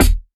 Dilla Kick 25.wav